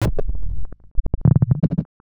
Glitch FX 38.wav